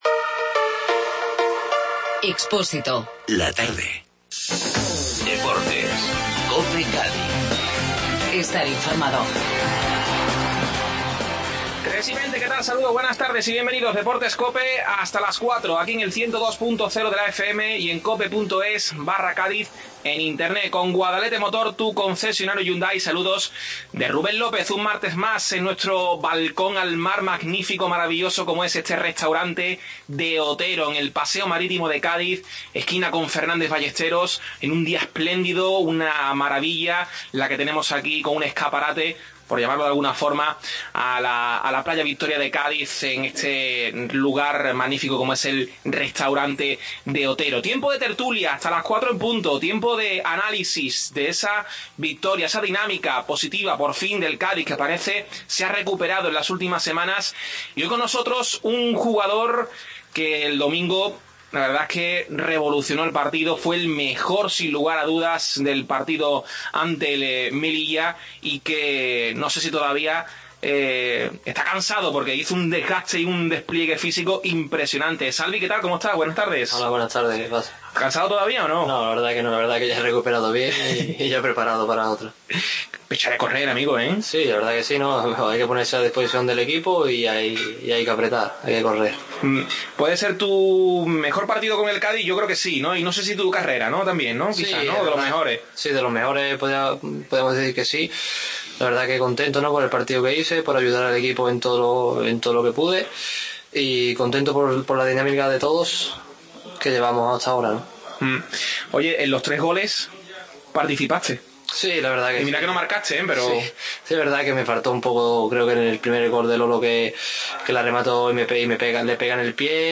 Desde el Restaurante De Otero